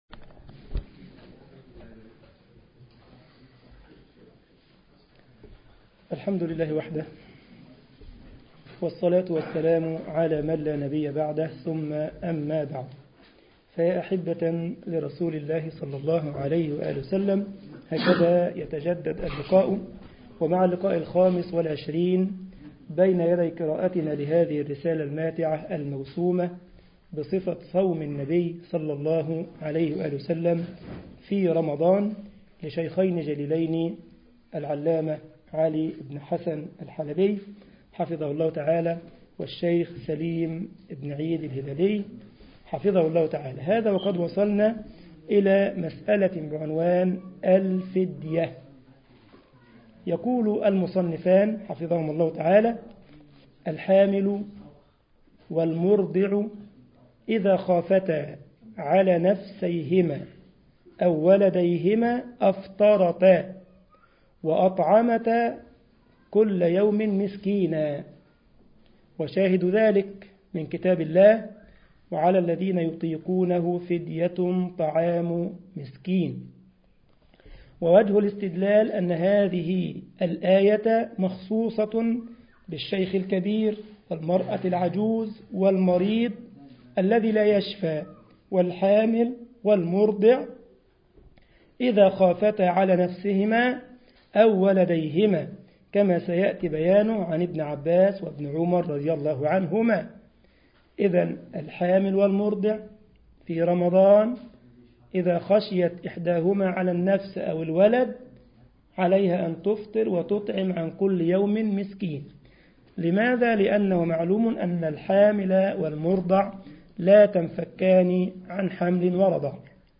مسجد الجمعية الاسلامية بالسارلند المانيا